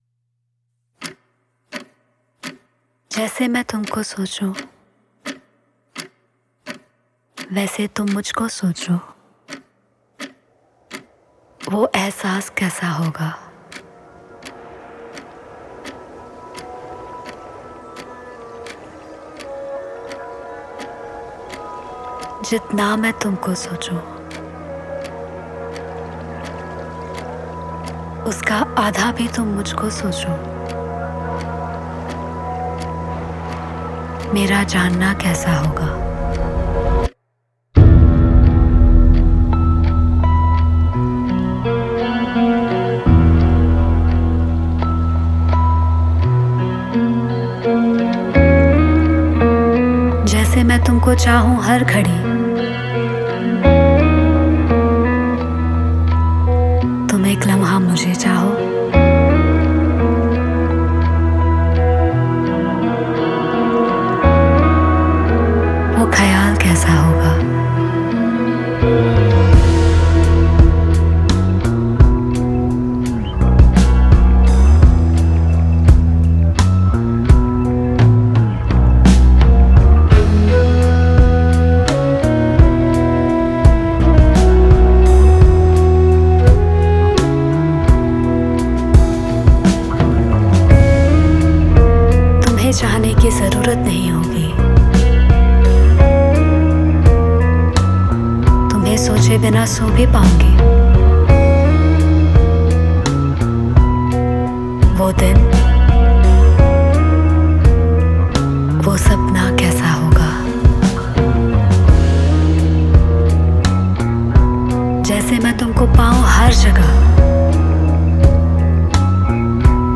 INDIPOP MP3 Songs
IndiPop Music Album